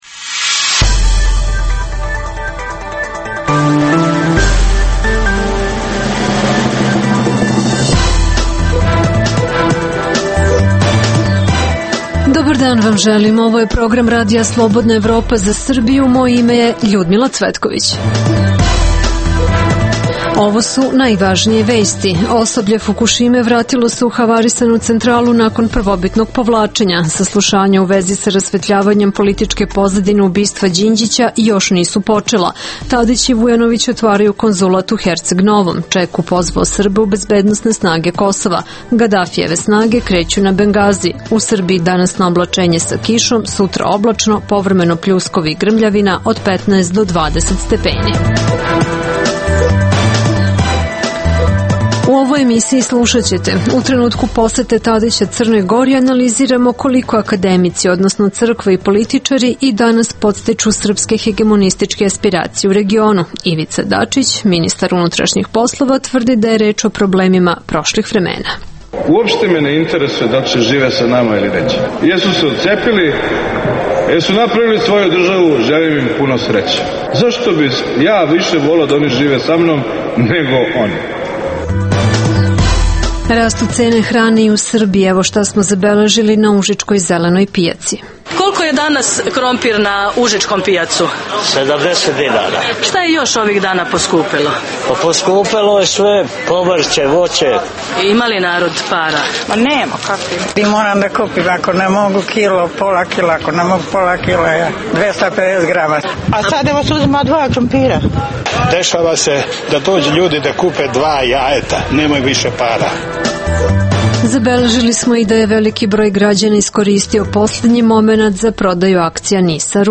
-Rastu cene hrane i u Srbiji. Posetili smo užičku zelenu pijacu.